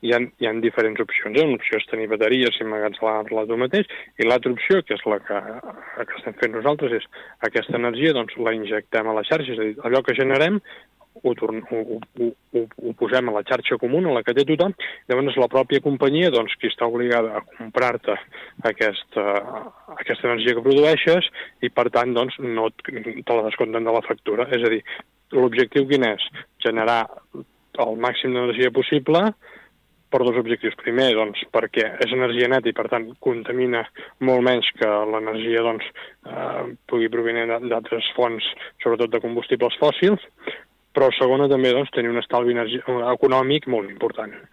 Buch ha parlat de les possibilitats d’optimització de l’energia generada per les plaques solars i ha detallat que, en el cas de Calella, s’ha optat per injectar-la directament a la xarxa elèctrica general.